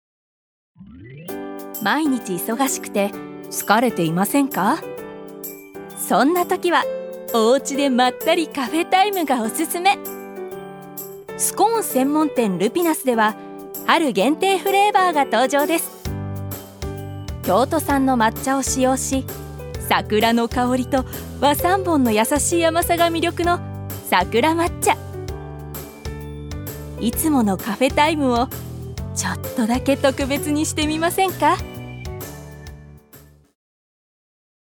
ジュニア：女性
ナレーション１